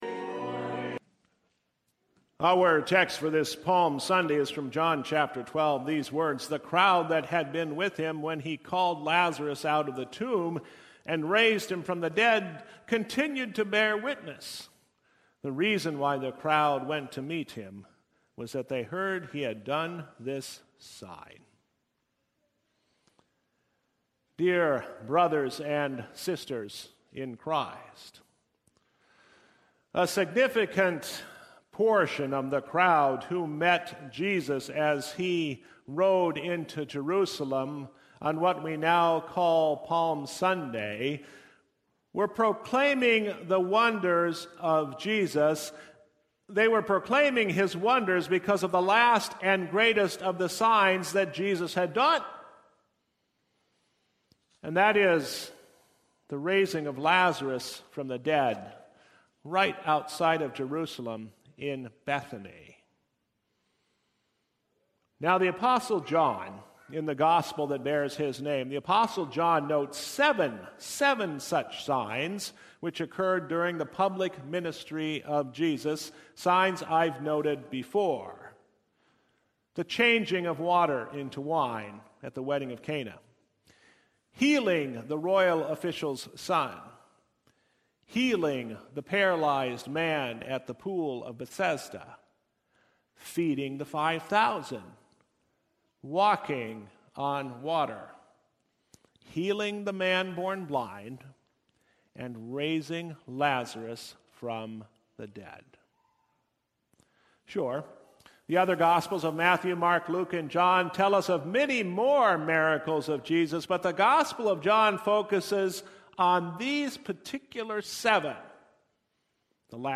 Palm-Sunday-2022.mp3